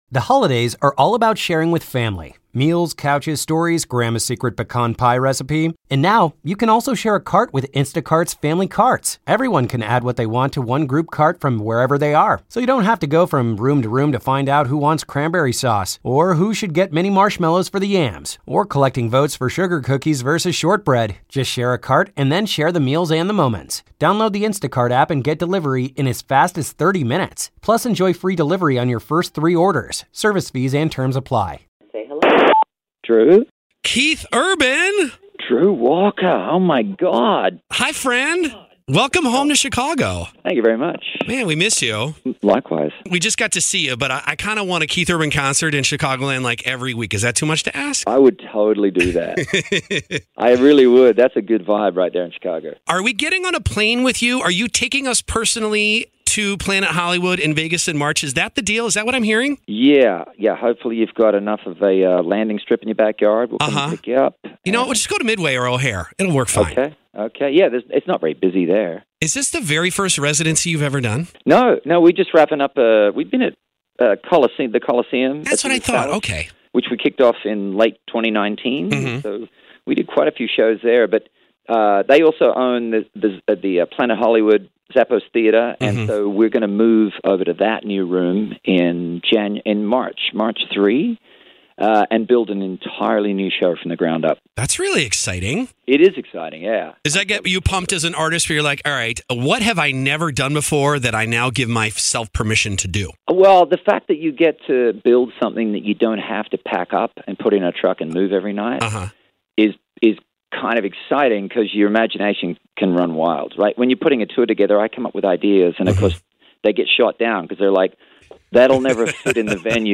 Love getting a phone call from Keith Urban any day of the week, but on the morning of the CMAs?